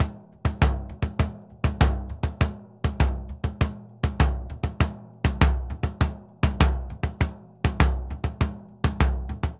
Bombo_Samba 100_1.wav